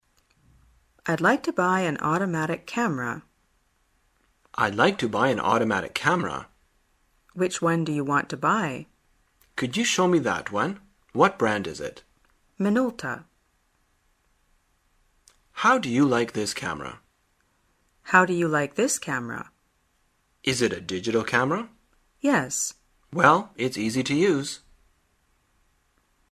旅游口语情景对话 第282天:如何买相机